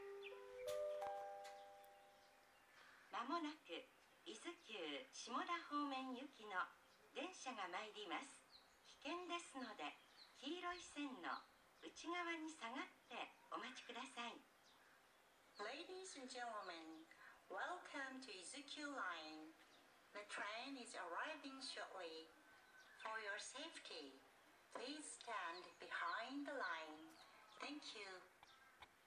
この駅では接近放送が設置されています。
接近放送普通　伊豆高原行き接近放送です。